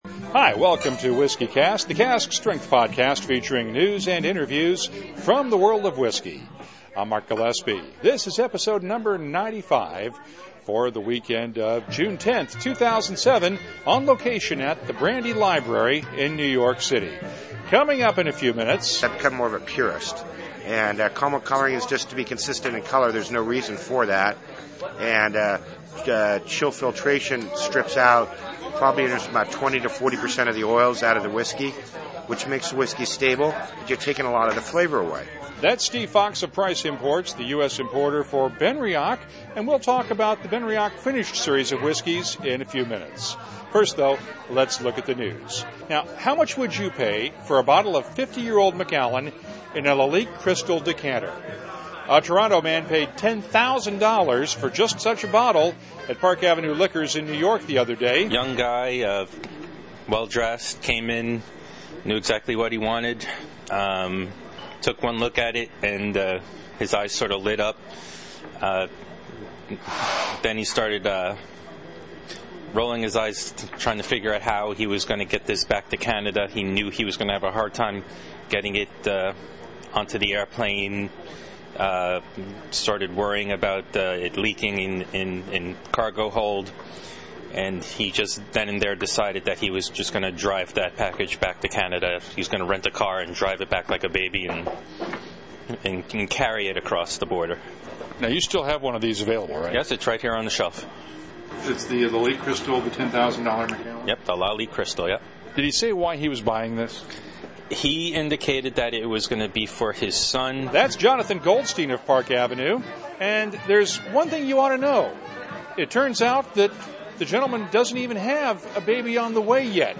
It’s a rare road trip for WhiskyCast, with a special episode taped on location at the Brandy Library in New York City.